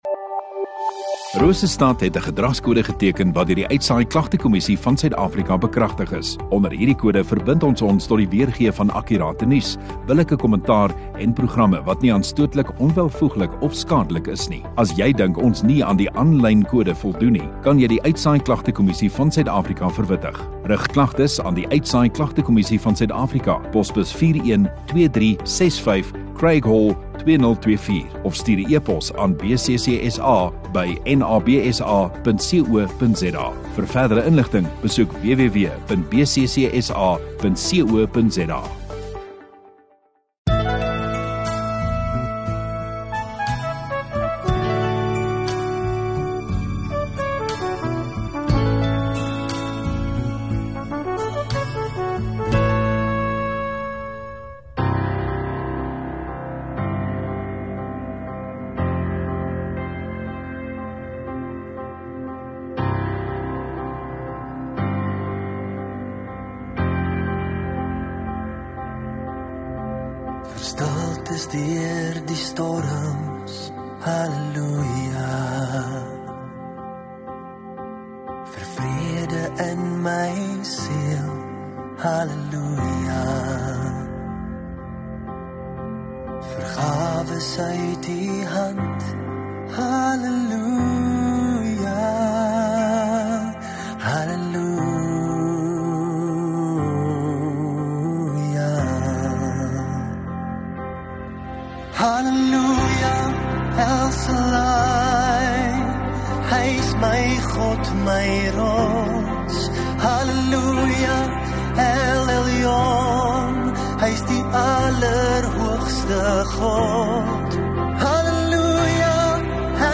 6 Sep Saterdag Oggenddiens